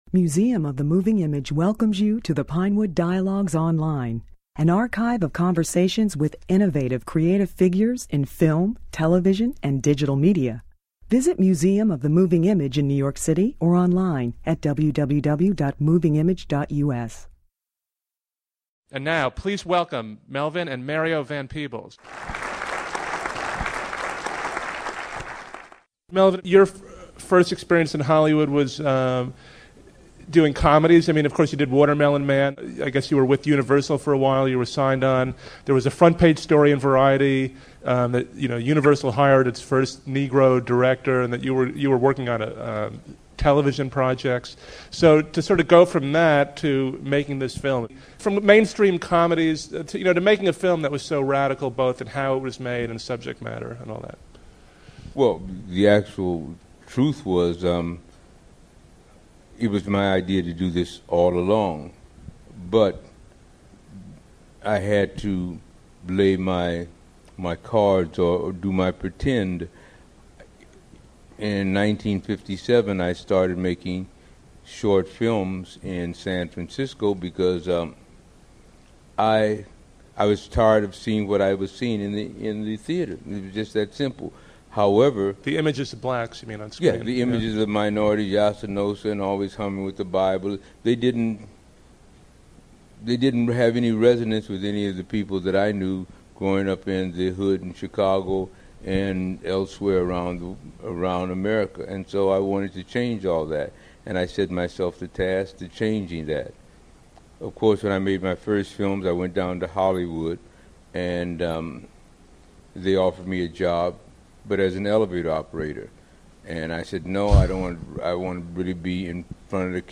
Melvin + Mario Van Peebles - Dialogues - Moving Image Source